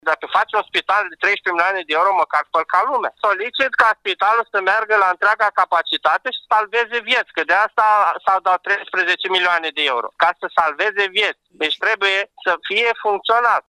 30-nov-ora-14-protestatar-2.mp3